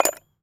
metal_small_movement_13.wav